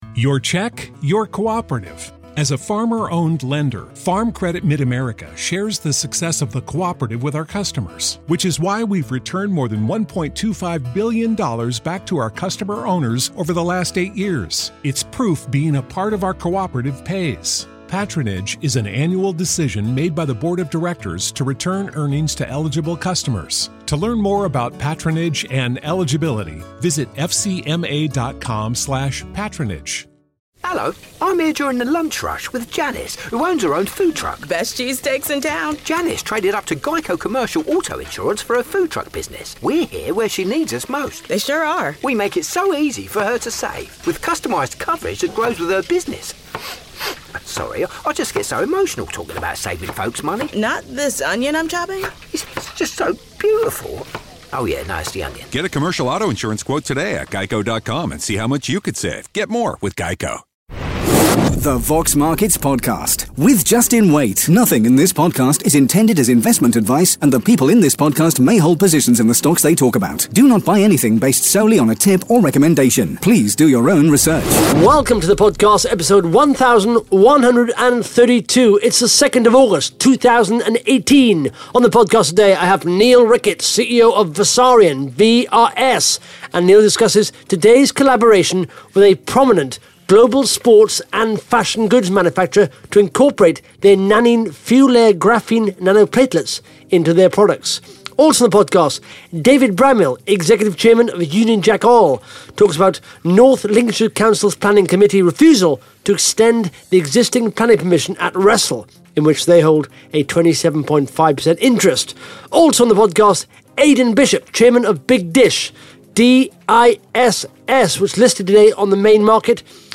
(Interview starts at 1 minute 41 seconds)